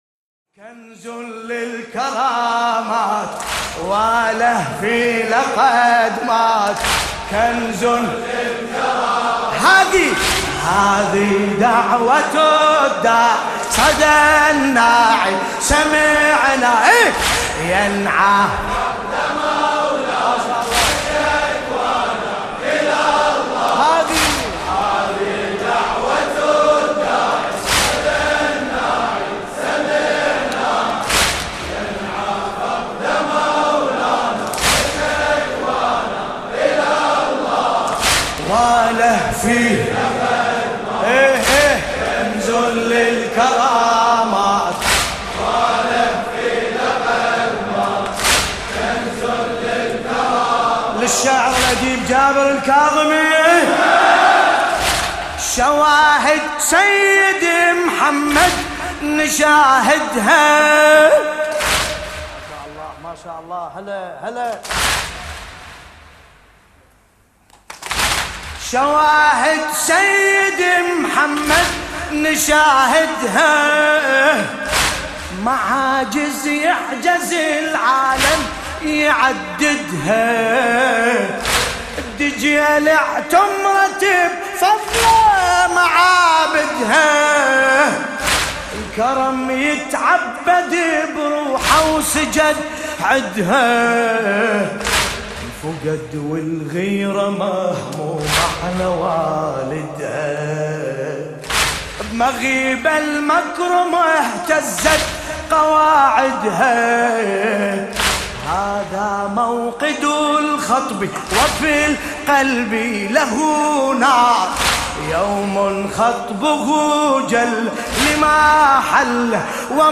ملف صوتی كنز للكرامات بصوت باسم الكربلائي